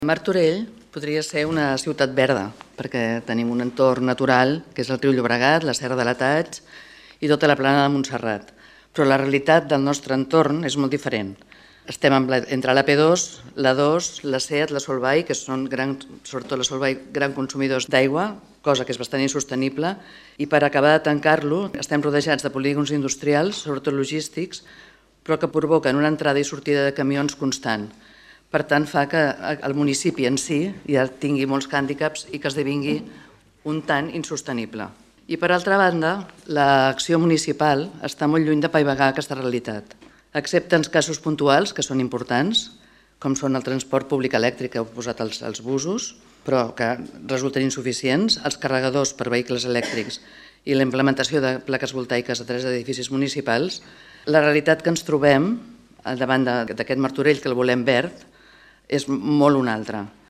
Àngels Cardona, regidora de Movem Martorell